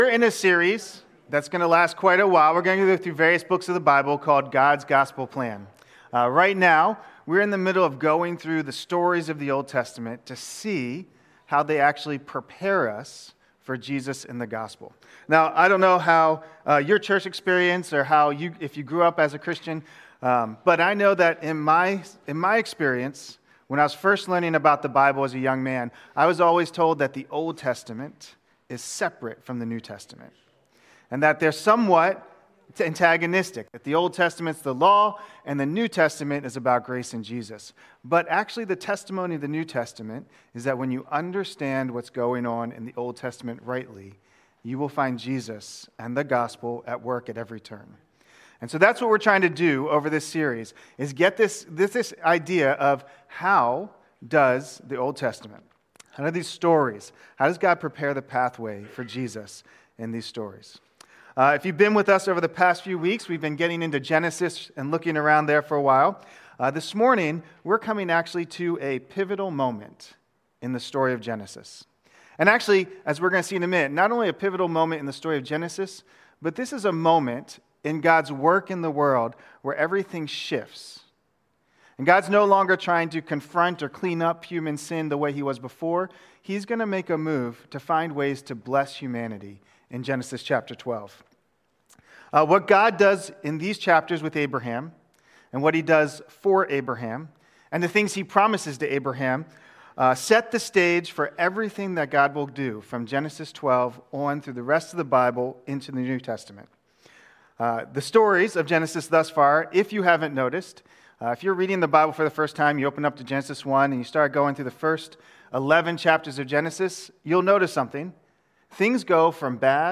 2026 Abraham: The Hope of the World Preacher